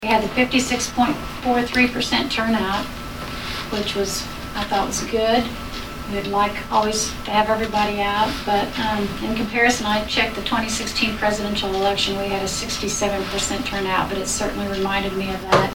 During the meeting of the county commission on Thursday, November 8, Clerk Debbie Russell applauded the voters, and said this reminded her a lot of the 2016 presidential election.